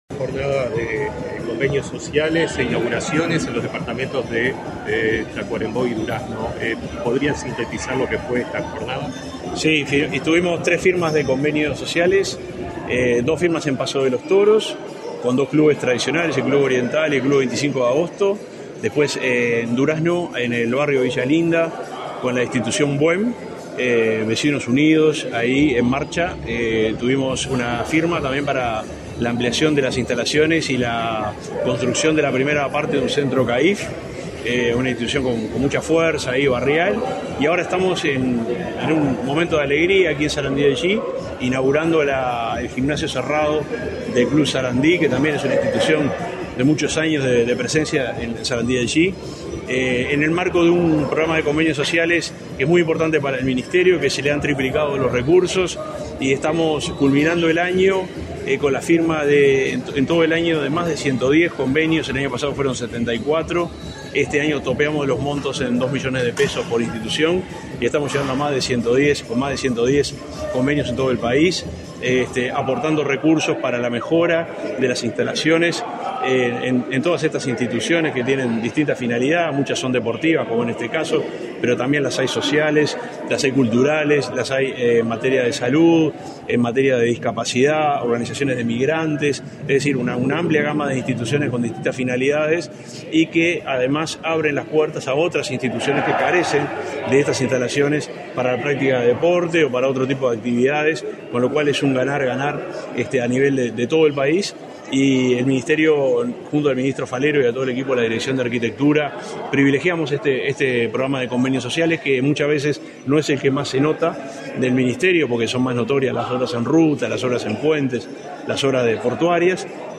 Declaraciones a la prensa del subsecretario del MTOP, Juan José Olaizola
En la oportunidad, el subsecretario Juan José Olaizola realizó declaraciones.